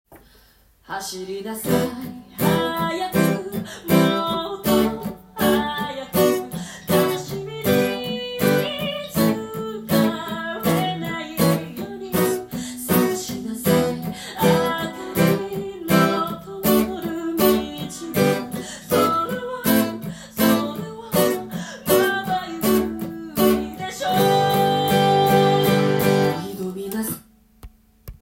アコースティックギターで弾ける
音源にあわせて譜面通り弾いてみました
コードは　Em、C、D、Bm、G、A、F#7/A#
「ジャッ」というパーカッシブなサウンドが出れば正解です。